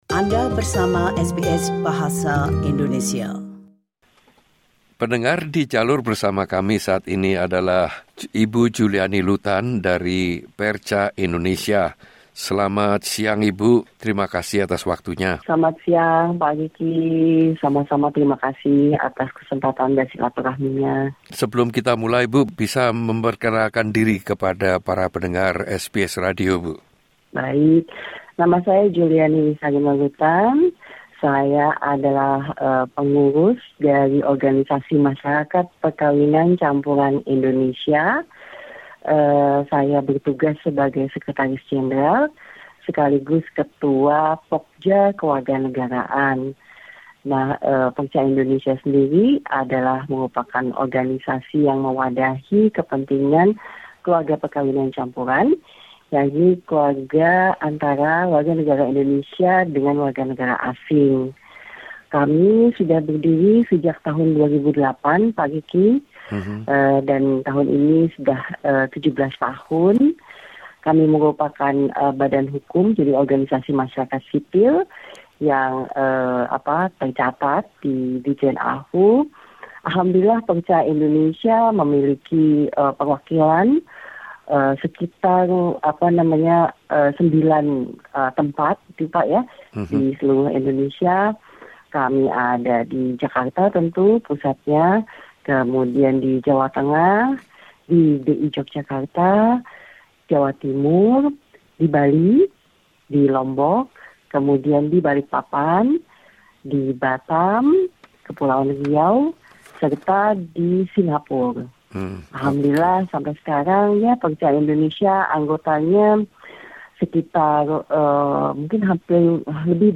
perCa indonesia Dalam perbincangan dengan SBS Indonesian